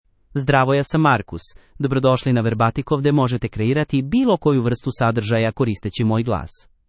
Marcus — Male Serbian AI voice
Marcus is a male AI voice for Serbian (Serbia).
Voice sample
Male
Marcus delivers clear pronunciation with authentic Serbia Serbian intonation, making your content sound professionally produced.